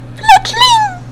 FLETCHLING.mp3